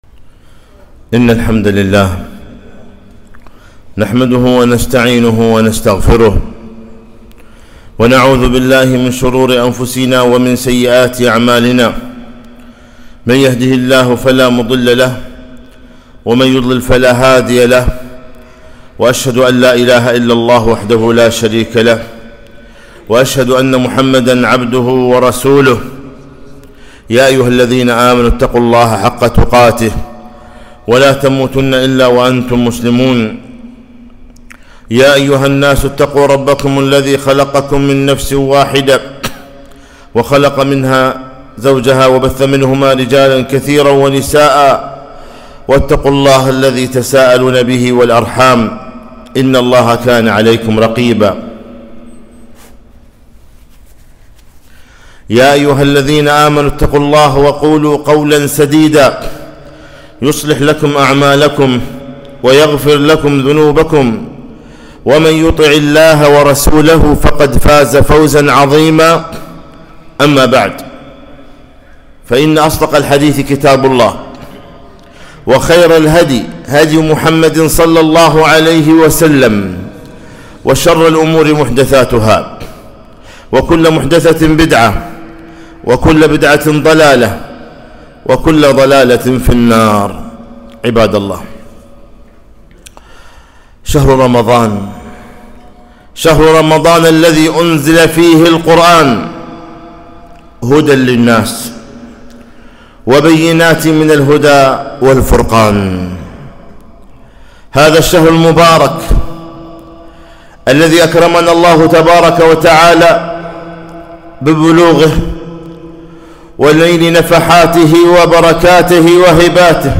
خطبة - شهر القرآن